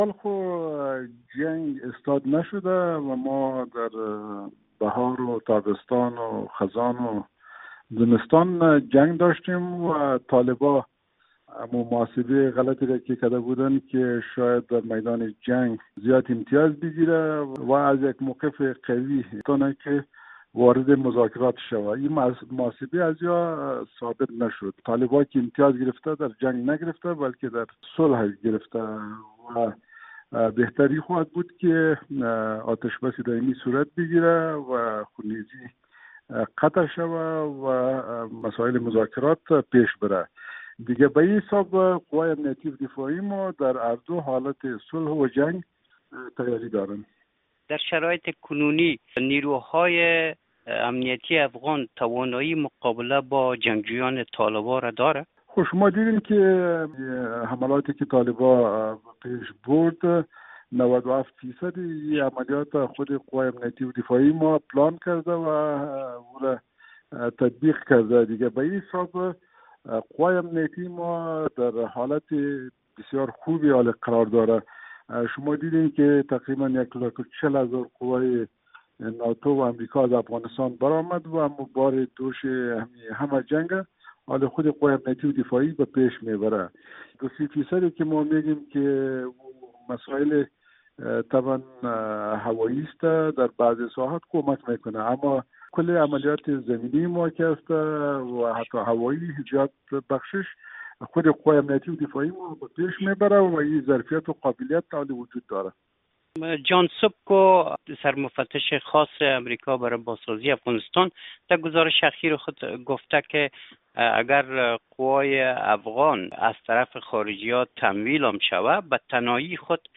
مصاحبه ویژه با شاه محمود میاخیل، معاون و سرپرست وزارت دفاع افغانستان
گفتگو با شاه محمود میاخیل